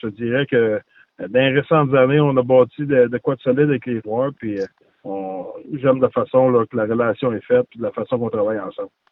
En entrevue, le coach du Mammoth de l’Utah est revenu sur les quelques changements qu’il a apportés au fil des années.